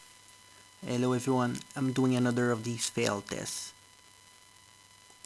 What I mind is the interference that seems to come from my laptop electronic or something like that.
That’s the frying mosquitoes (whining) sound when the 5 volt USB power from the computer is not “clean.” The microphone is combining your voice with electrical trash in the cable.